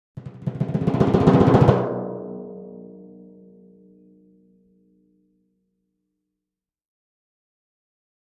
Timpani, (Hands), Roll Accent, Type 4 - Short Crescendo